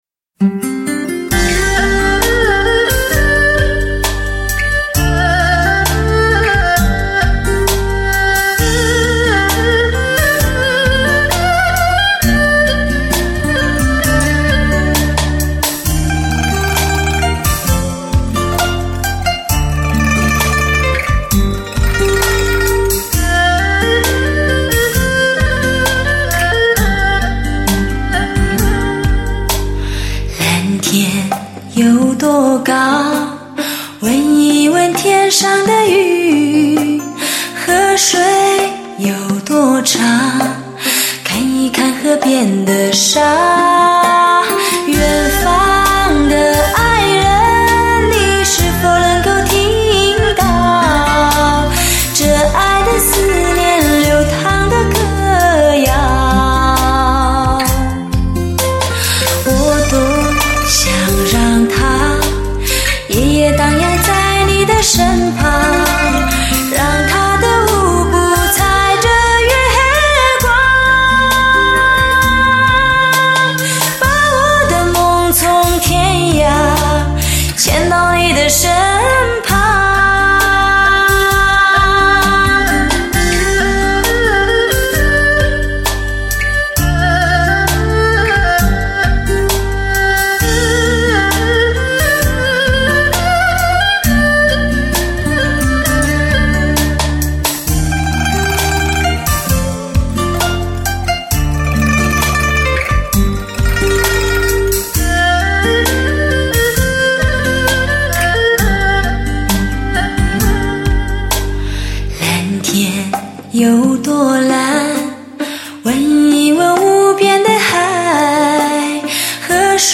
汽车专业发烧汽车唱片，高临场感360°德国黑胶LP-CD。
为低音质MP3